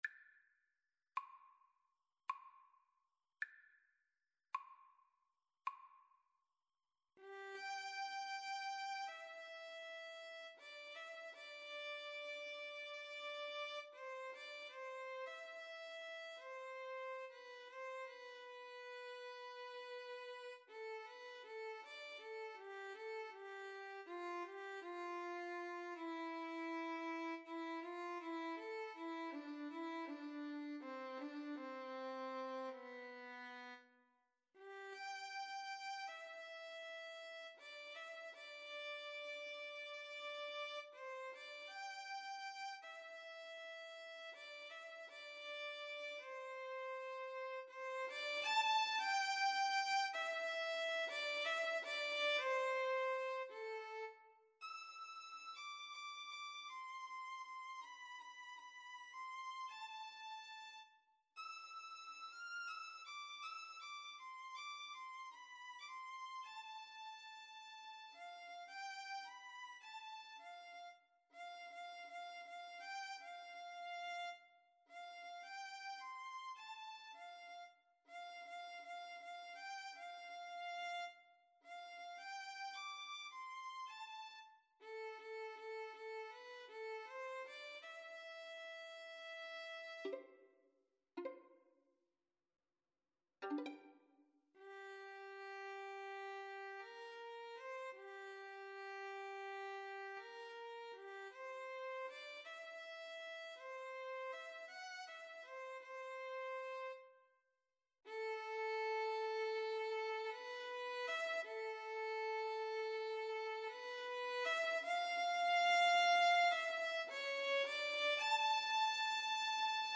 9/8 (View more 9/8 Music)
Andante tres expressif
Classical (View more Classical Violin-Cello Duet Music)